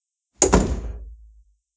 doorClosing.wav